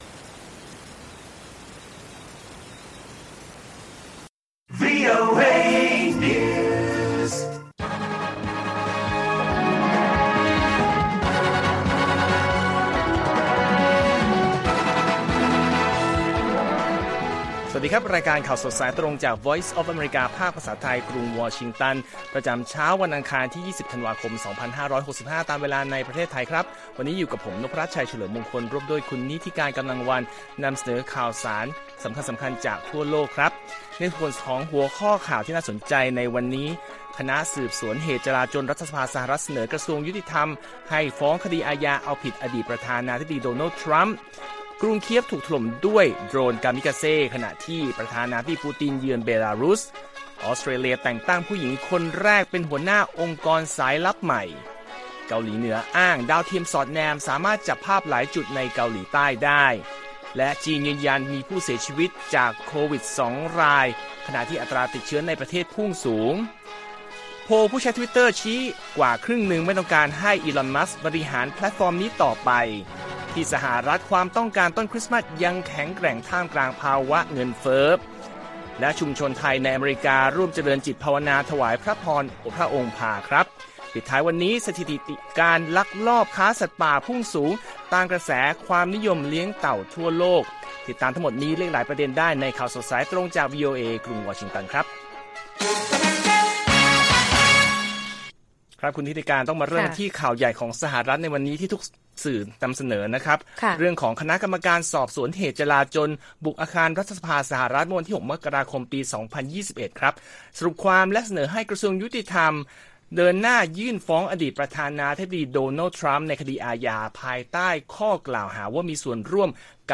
ข่าวสดสายตรงจากวีโอเอไทย อังคาร ที่ 20 ธ.ค. 65